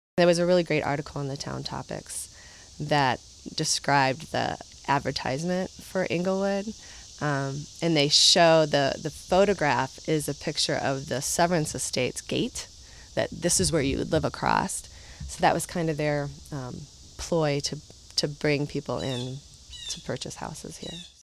| Source: Cleveland Regional Oral History Collection